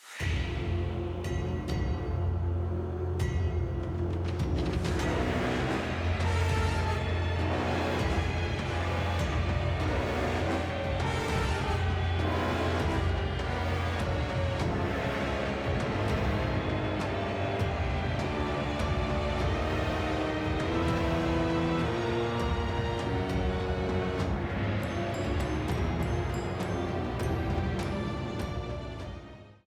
A theme
Ripped from the game
clipped to 30 seconds and applied fade-out